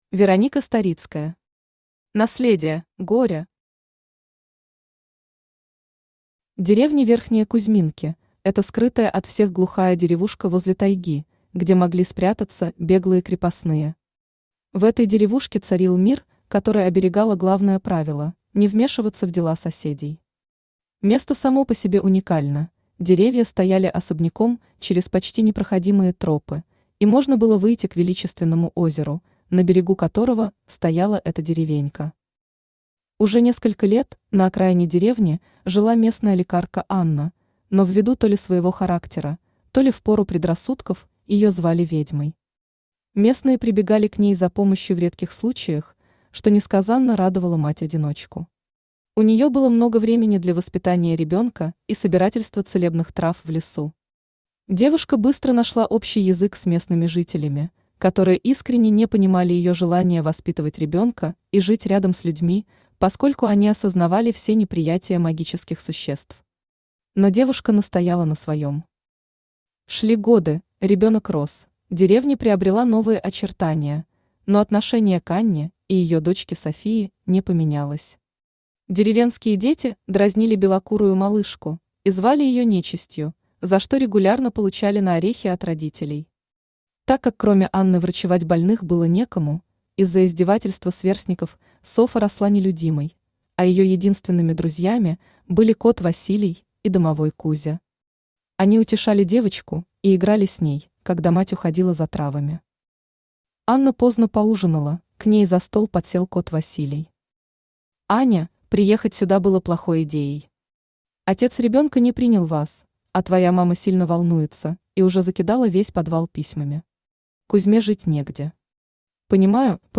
Аудиокнига Наследие | Библиотека аудиокниг
Aудиокнига Наследие Автор Вероника Андреевна Старицкая Читает аудиокнигу Авточтец ЛитРес.